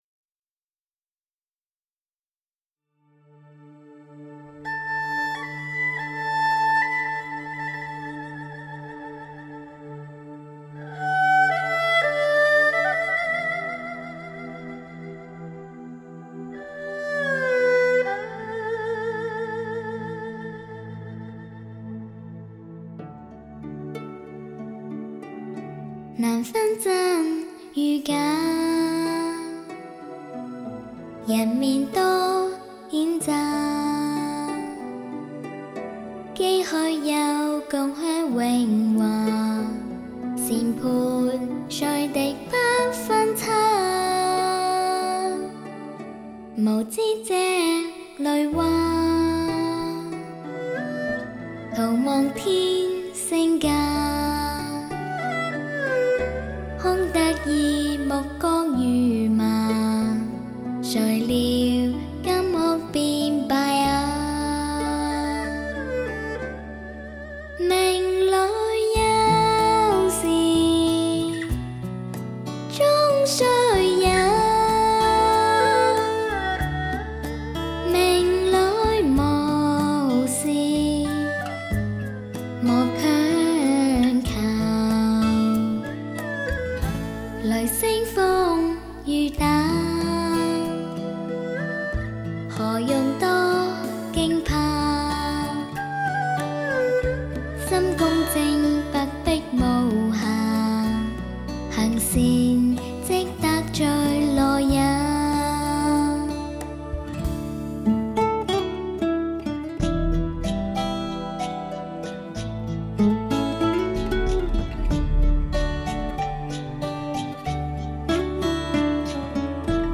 监听级童声民歌6.1 DTS
天籁童声纯净中的极致
6.1环绕声DTS CD把“现代理论物理学”和“声学”的最新科技成果应用到录音艺术领域，
音质透彻晶莹，音响效果层次分明，声音结像力极佳，演唱音乐表现清新洒脱。